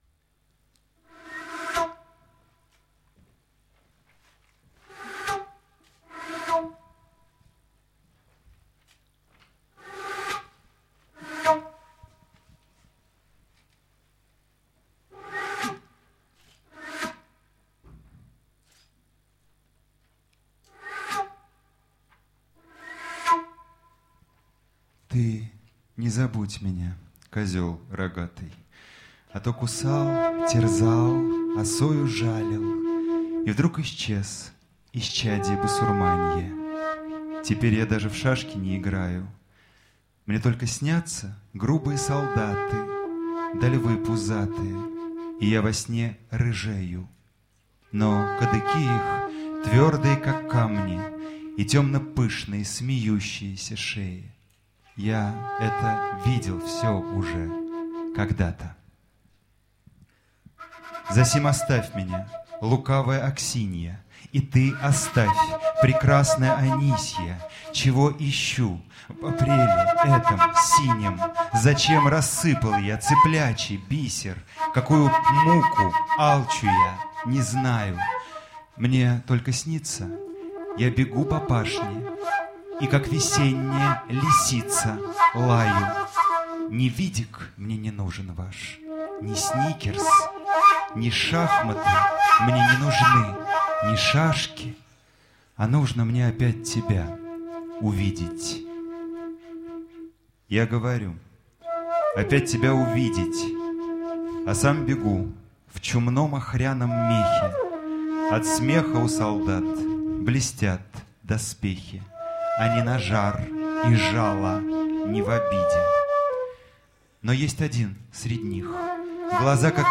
Концерт в «Практике», 2009 год.